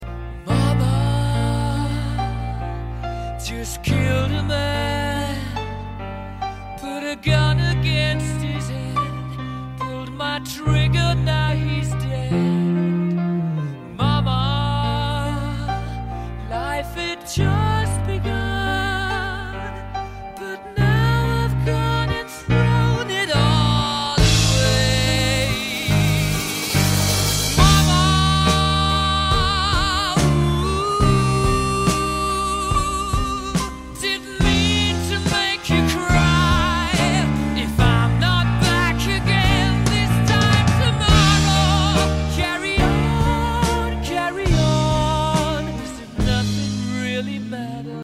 Quantos minutos tem esse clássico do rock?